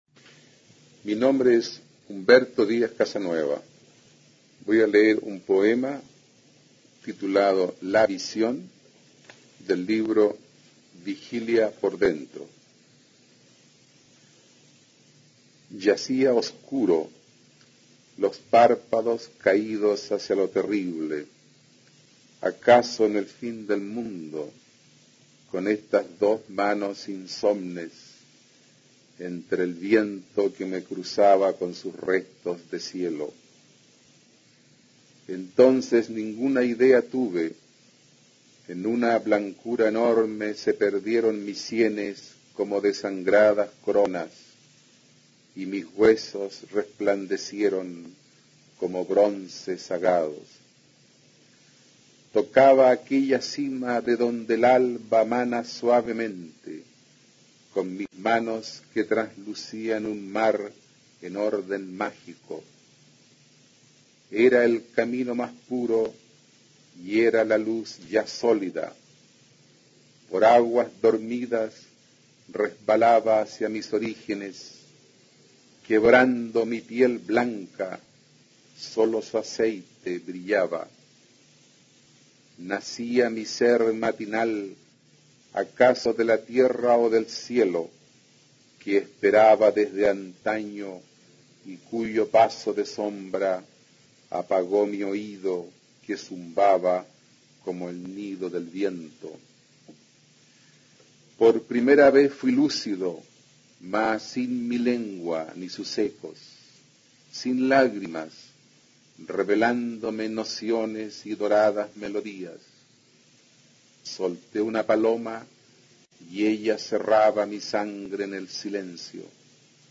A continuación se puede escuchar a Humberto Díaz-Casanueva, destacado autor de las vanguardias hispanoamericanas y Premio Nacional de Literatura en 1971, recitando su poema La visión, del libro "Vigilia por dentro" (1931).